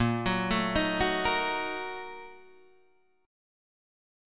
A# Major 7th Suspended 4th Sharp 5th
A#M7sus4#5 chord {6 9 7 8 7 6} chord